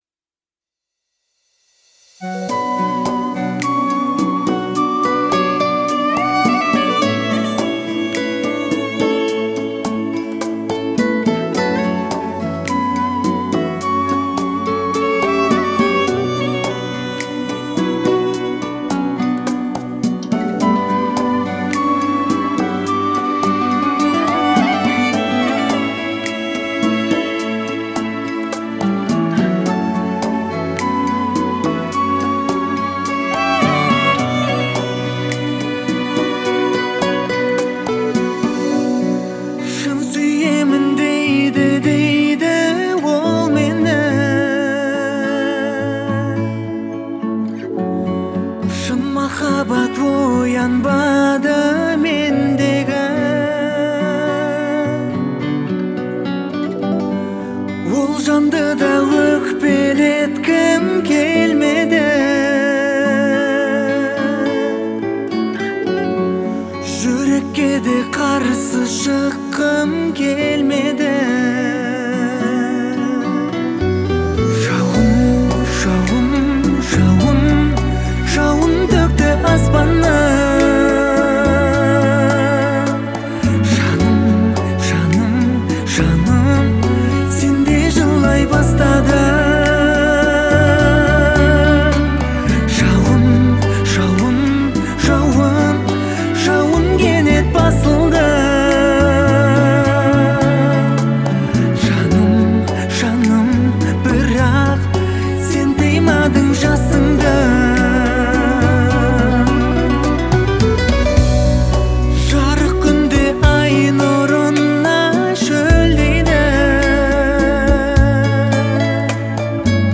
романтическая песня